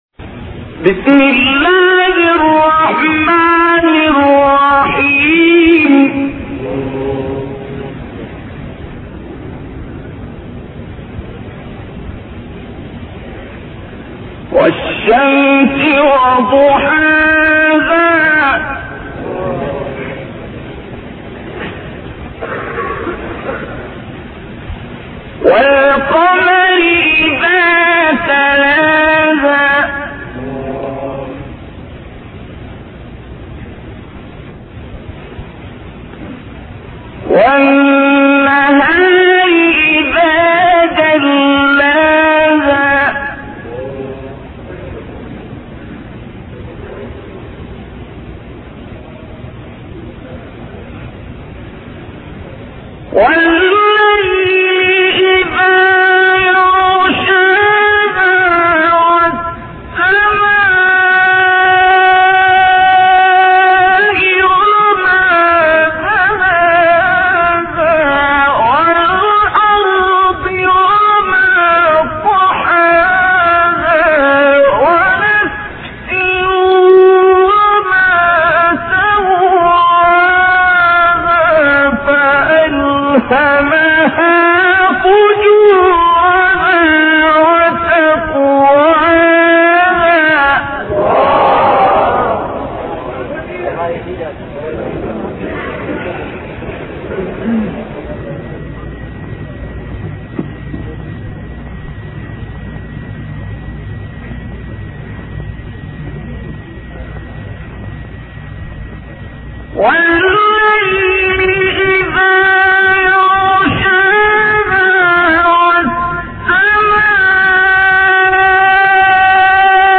تلاوت سوره شمس با صدای عبدالباسط محمد عبدالصمد